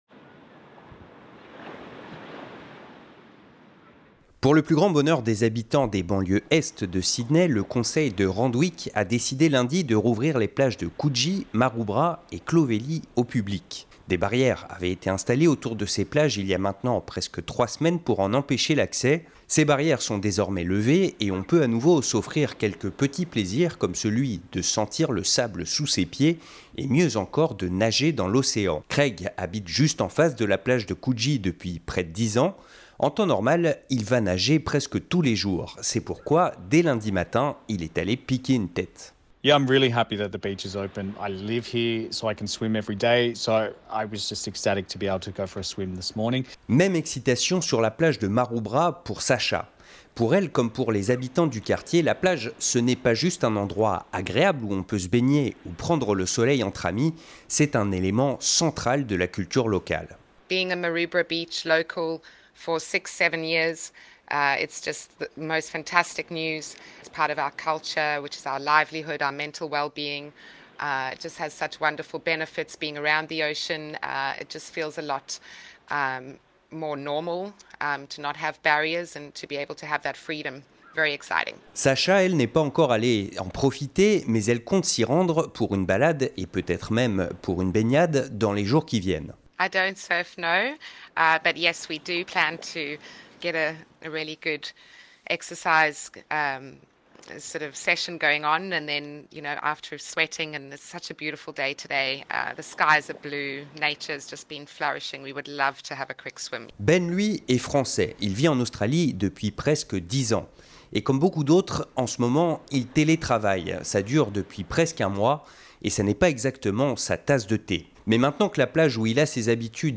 Les habitants de Randwick sont ravis de pouvoir à nouveau profiter des plages de Coogee, Clovelly et Maroubra, même si pour l'instant on ne peut s'y rendre que pour faire du sport. Nous avons interrogé les habitants du quartier, ils nous disent ce qu'ils en pensent.